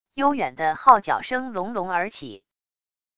悠远的号角声隆隆而起wav音频